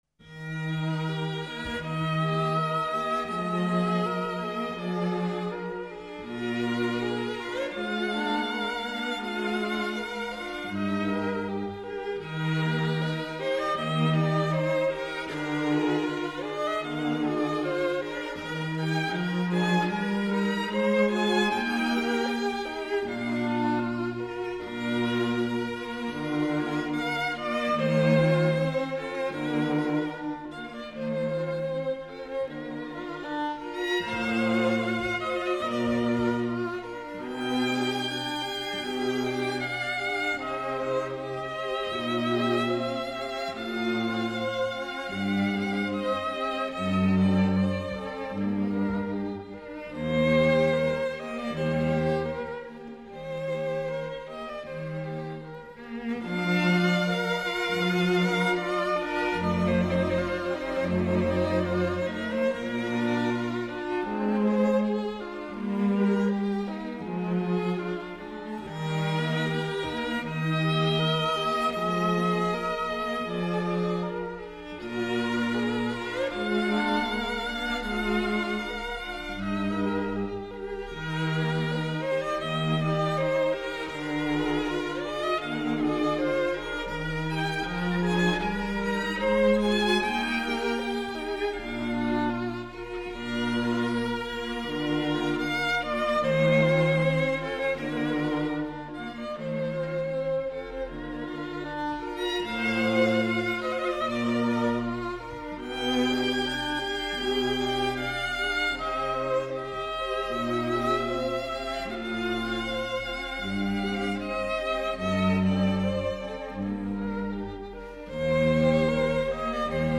String Quartet in B flat major
Adagio